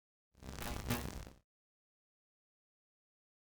meleeattack-swoosh-magicaleffect-group01-lightning-03.ogg